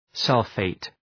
Shkrimi fonetik {‘sʌlfeıt}